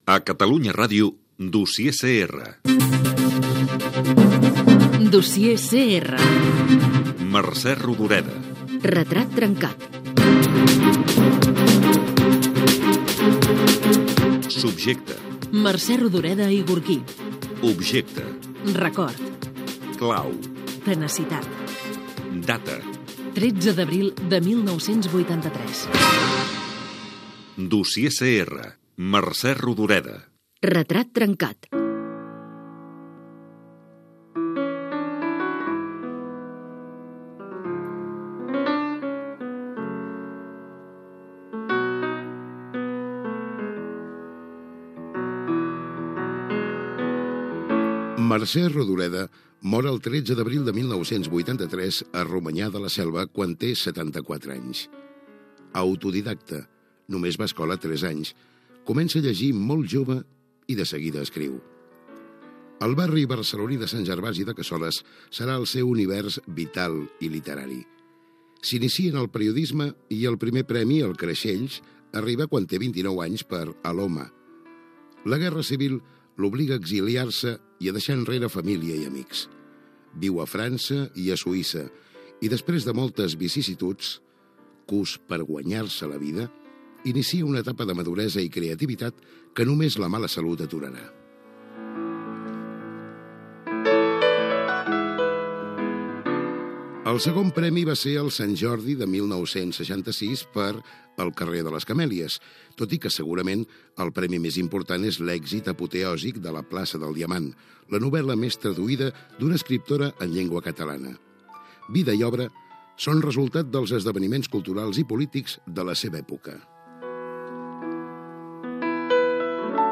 "Mercè Rodoreda: retrat trencat". Careta del programa dedicat a l'esciptora Mercè Rodoreda, perfil biogràfic i obra